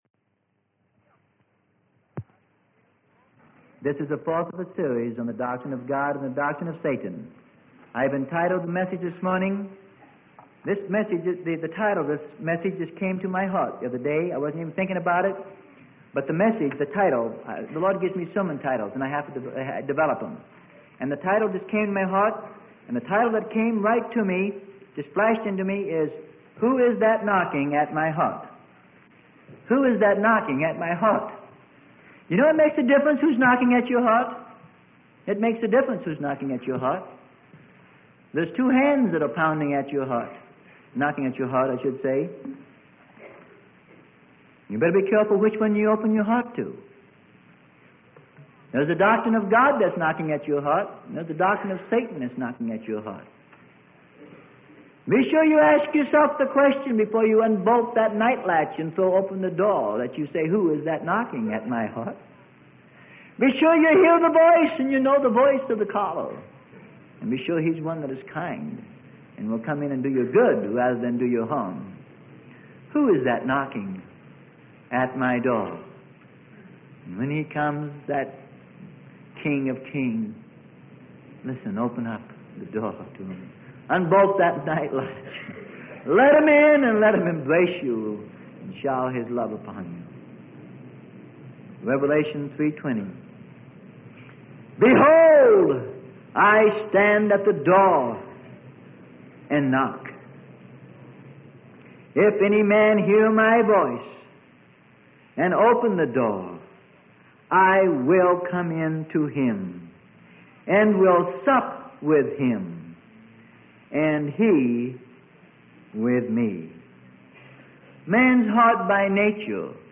Sermon: God's Doctrine and Satan's Doctrine - Part 4 - Who's That Knocking at My Heart - Freely Given Online Library